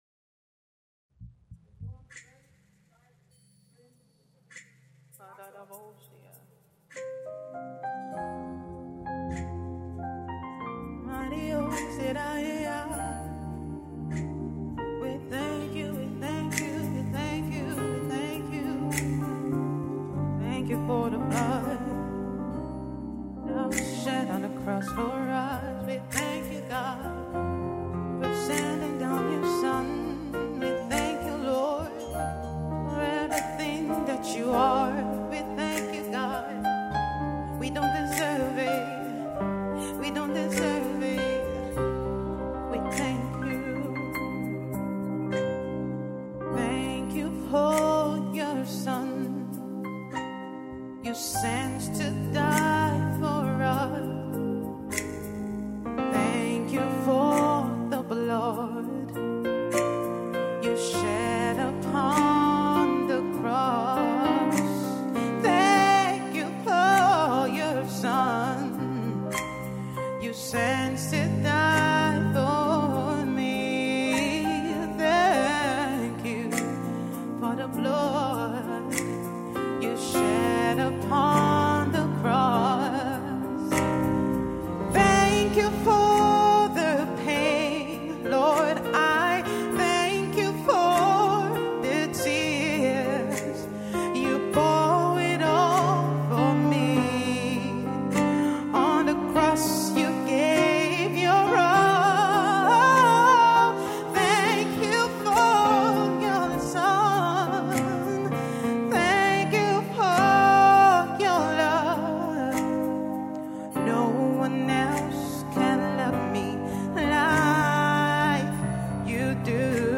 Abuja-based gospel music maestro